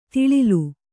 ♪ tiḷilu